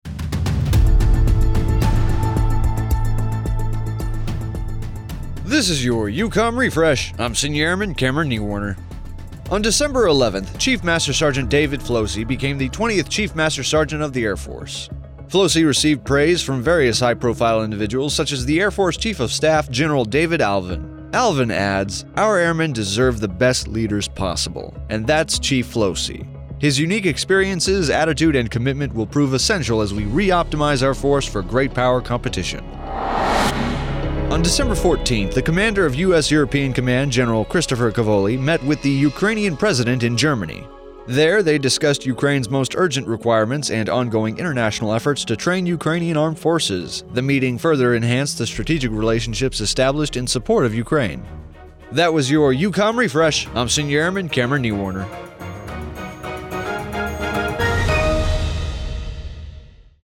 Regional Media Center AFN Europe